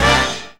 JAZZ STAB 24.wav